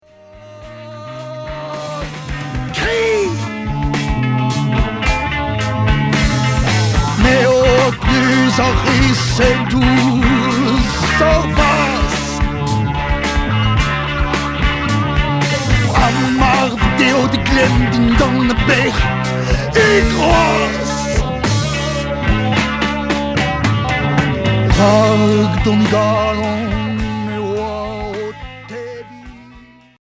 Folk progressif éléctrique